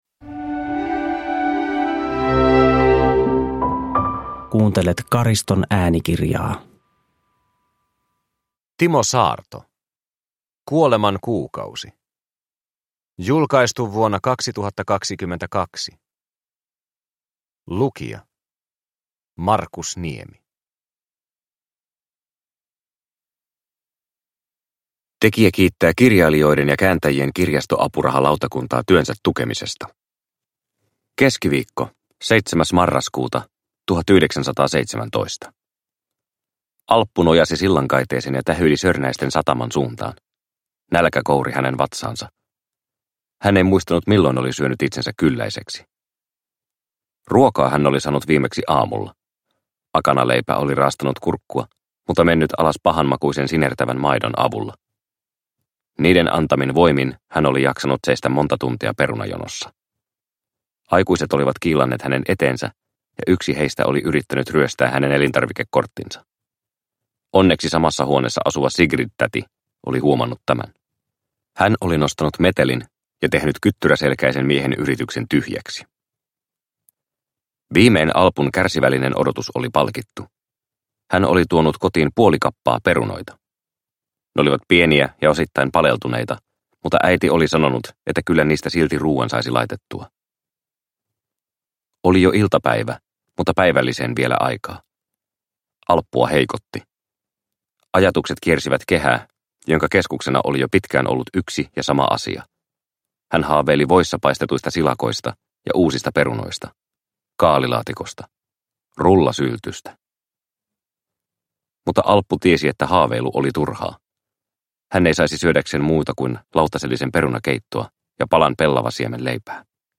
Kuoleman kuukausi – Ljudbok – Laddas ner